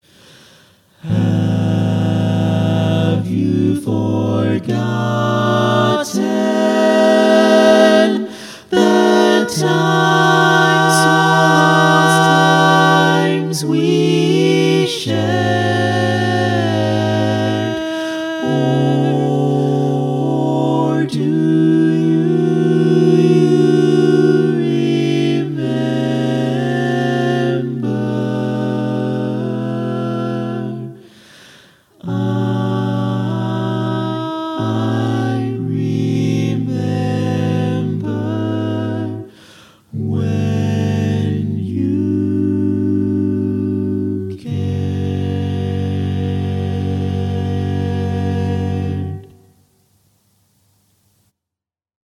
Key written in: F# Major
How many parts: 4
Type: Barbershop
All Parts mix: